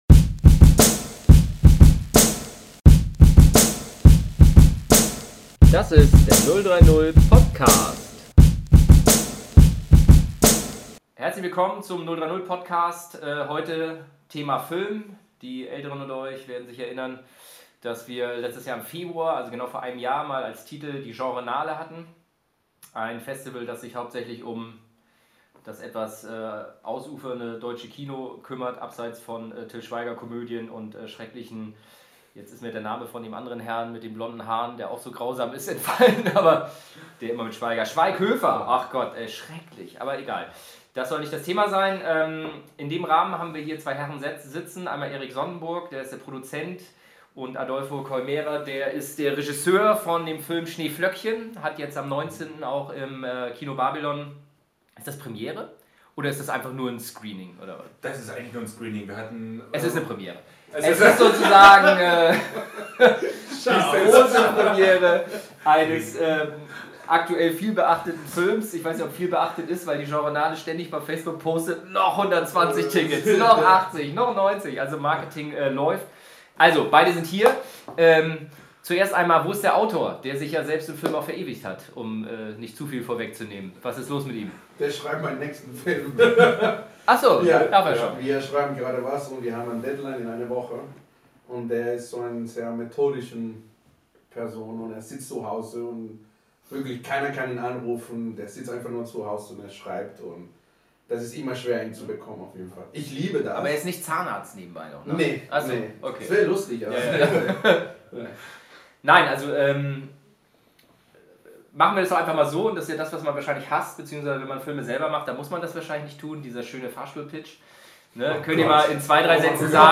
Der [030] Podcast mit den Machern von SCHNEEFLÖCKCHEN:
Von der Idee, über den Pitch bis zur Realisierung und überhaupt das deutsche Kino als Ganzes Drama. Knapp eine Dreiviertelstunde filmischer Nerdtalk. Wir haben viel gelacht (und gelabert, ja zugegeben) aber es war uns eine große Freude.